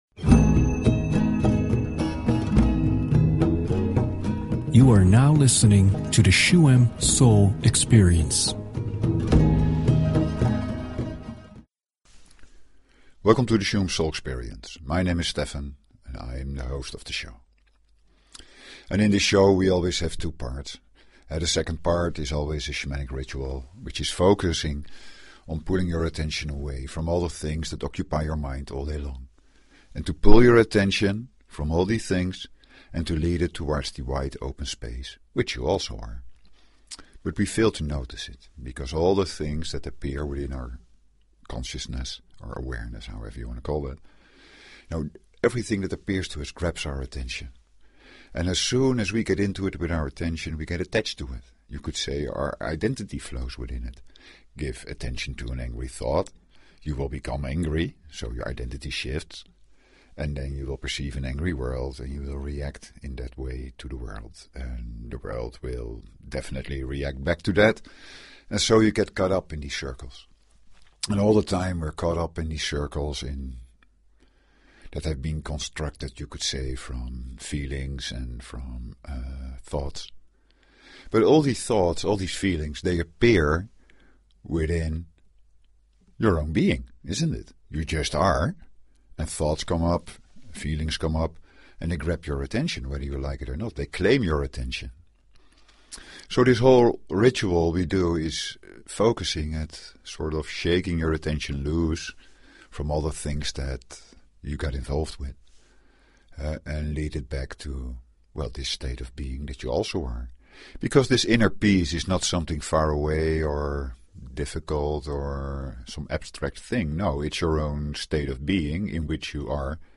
Talk Show Episode, Audio Podcast, Shuem_Soul_Experience and Courtesy of BBS Radio on , show guests , about , categorized as
Every instrument has a specific meaning and effect. The shaman drum for instance has a high healing quality and the bell at the beginning and the end is to mark this period of your time for meditation.